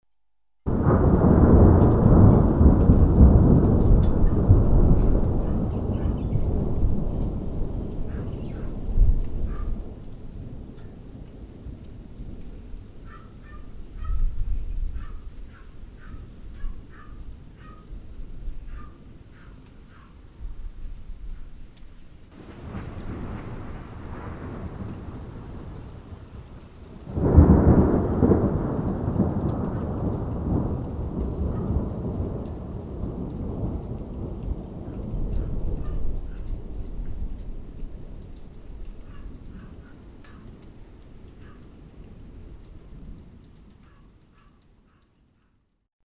雷音(0:46)
1997年夏自宅にて録音。